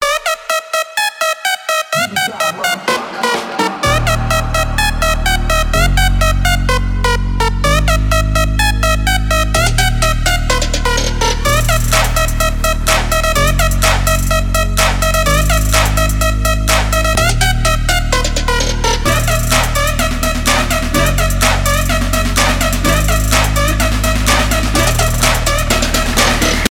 громкие
electro house , клубные